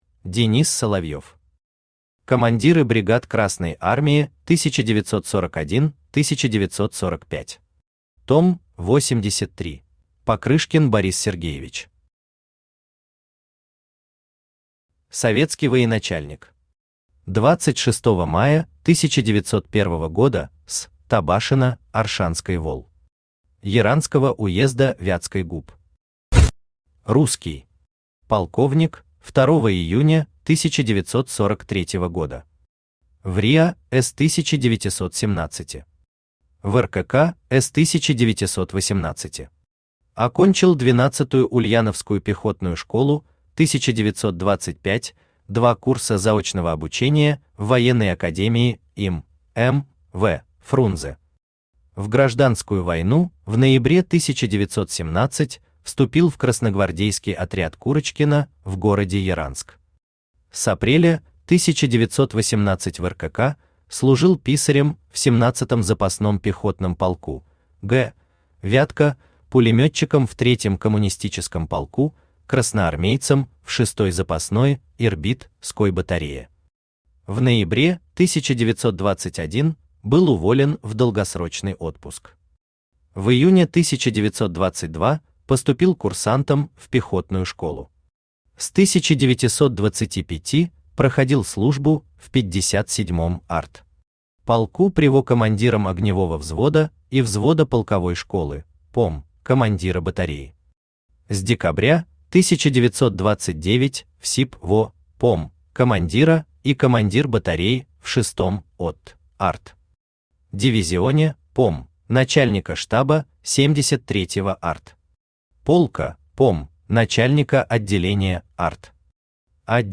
Том 83 Автор Денис Соловьев Читает аудиокнигу Авточтец ЛитРес.